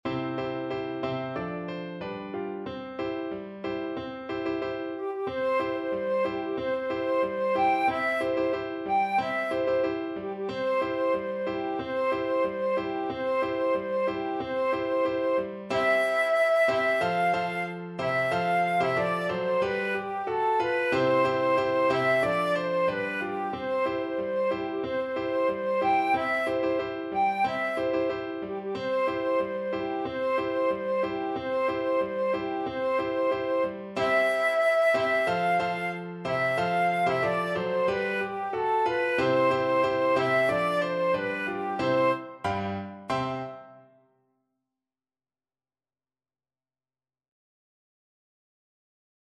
Flute version
2/2 (View more 2/2 Music)
Happily =c.92
Classical (View more Classical Flute Music)